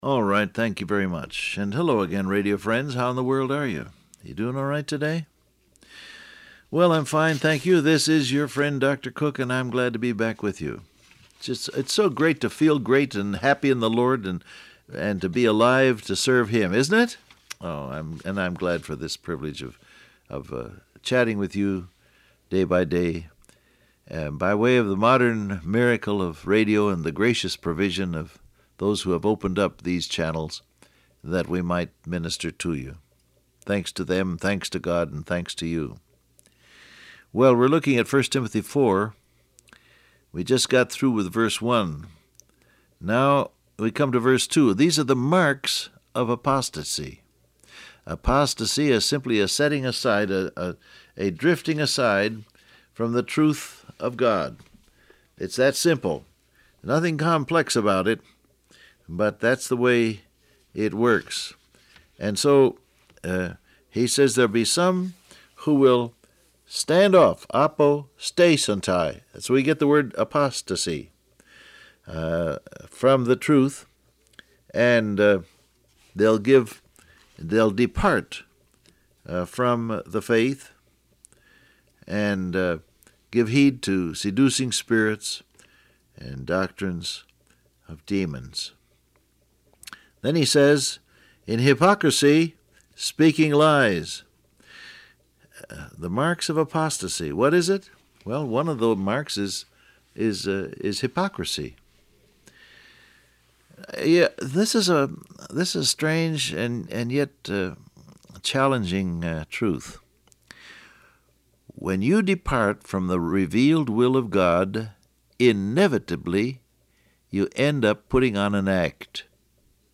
Download Audio Print Broadcast #6712 Scripture: 1 Timothy 4:2 Topics: Hypocrisy , Guidance , Apostasy , Steering Transcript Facebook Twitter WhatsApp Alright, thank you very much.